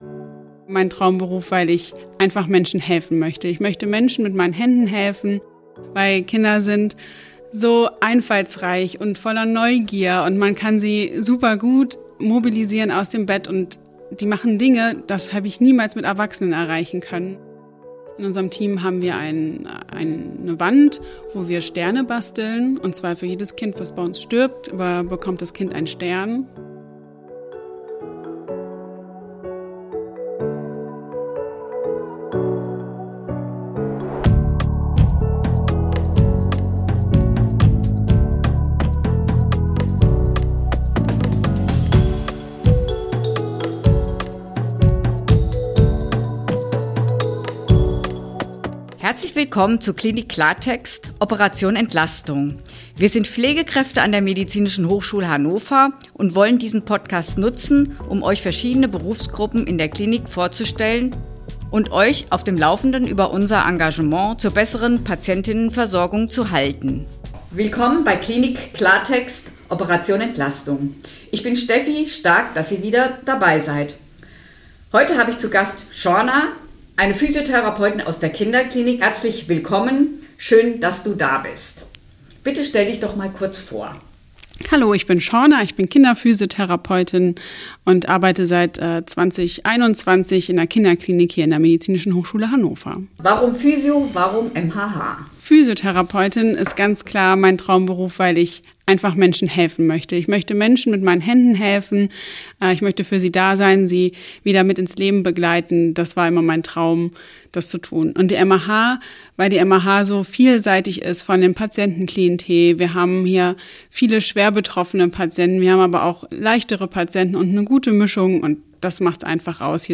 Kinderphysiotherapie – Ein Interview